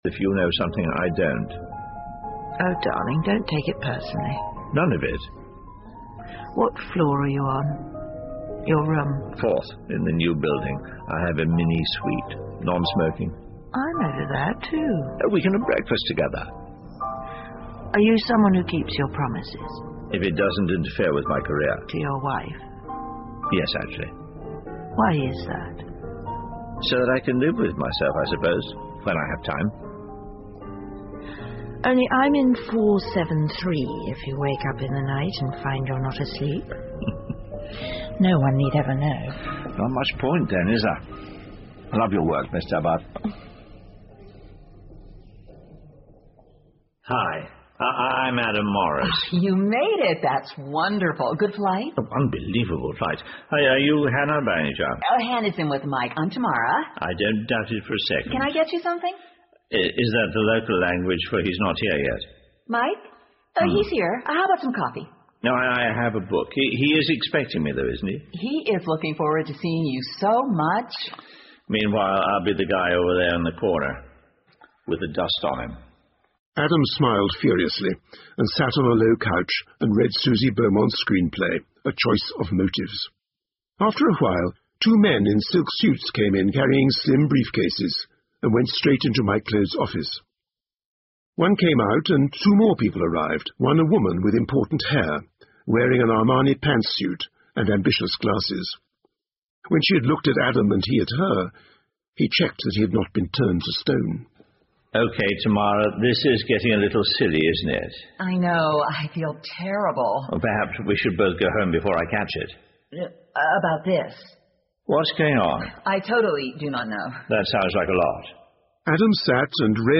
英文广播剧在线听 Fame and Fortune - 55 听力文件下载—在线英语听力室